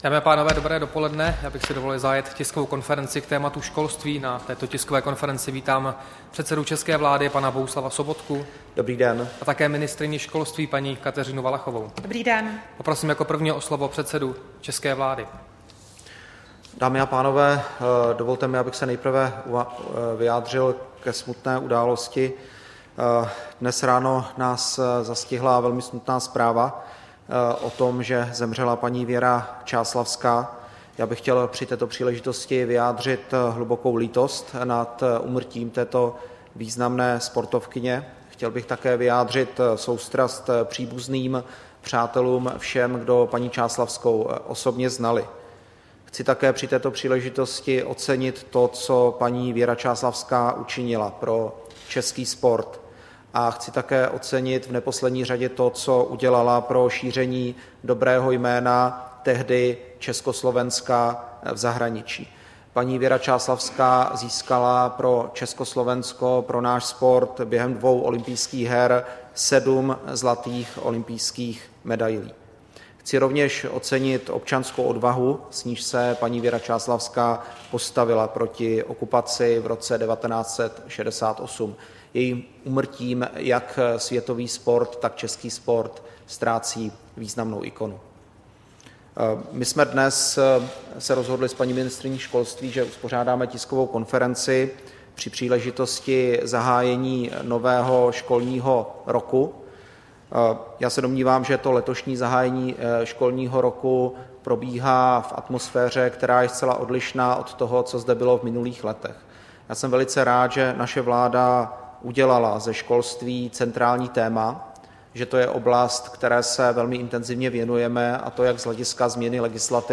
Tisková konference premiéra Sobotky a ministryně školství, mládeže a tělovýchovy Valachové k zahájení nového školního roku, 31. srpna 2016